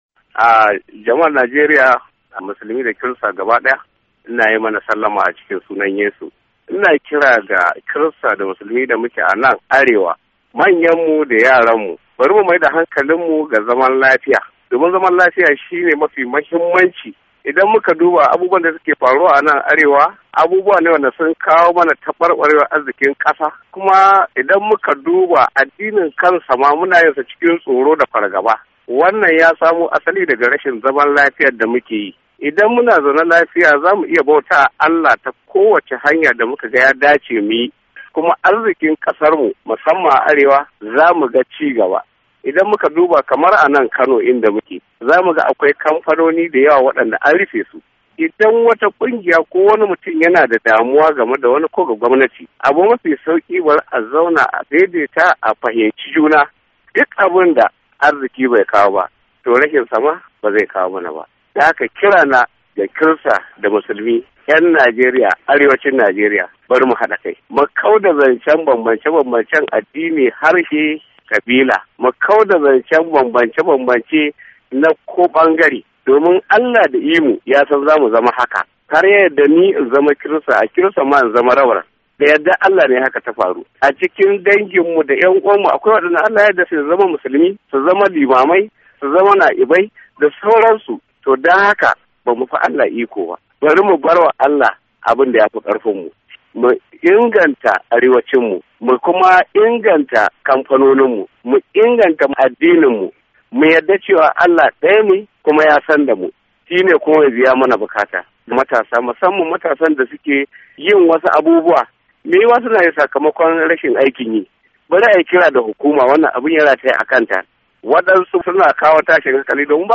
Jawabin